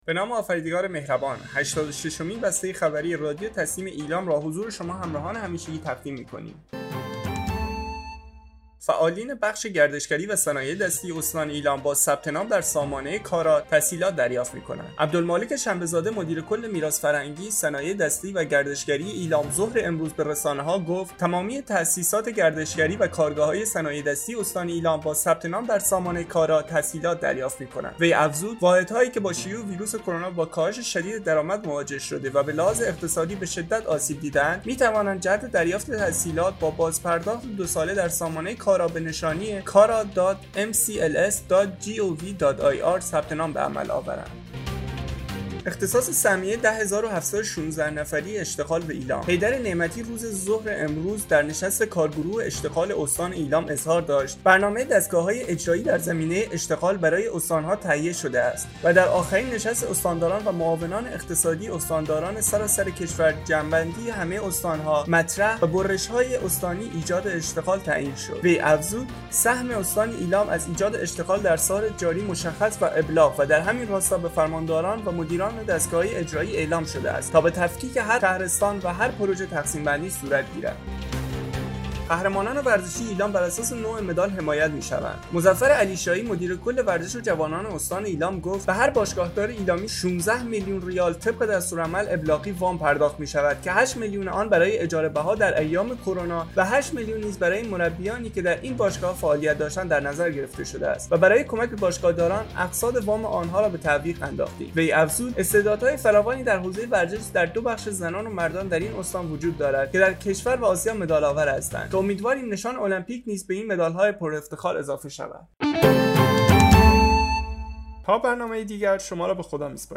به گزارش خبرگزاری تسنیم از ایلام, هشتاد و ششمین بسته خبری رادیو تسنیم استان ایلام با خبرهایی چون، فعالیت بخش گردشگری و صنایع دستی استان ایلام با ثبت‌نام در سامانه کارا تسهیلات دریافت می‌کنند، اختصاص سهمیه 10 هزار و 716 نفر اشتغال به ایلام و قهرمانان ورزشی ایلام بر اساس نوع مدال حمایت می‌شوند، منتشر شد.